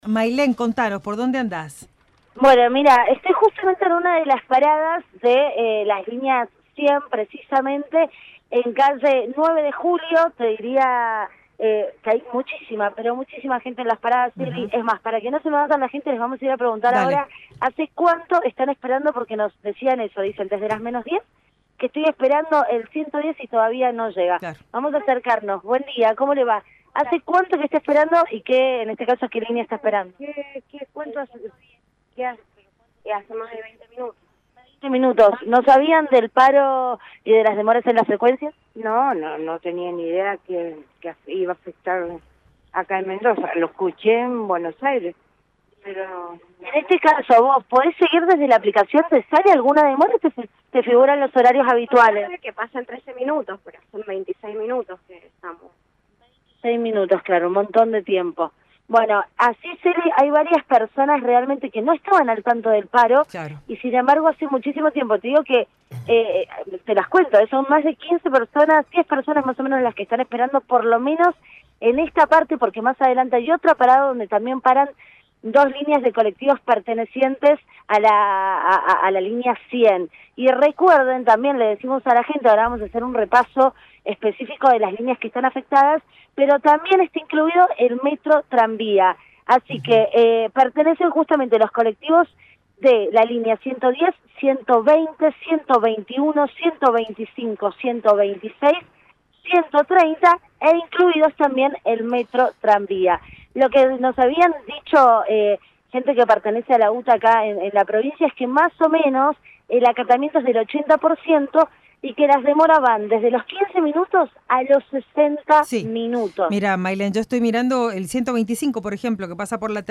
LVDiez - Radio de Cuyo - Móvil de LVDiez desde parada colectivos línea 110, calle 9 de julio Ciudad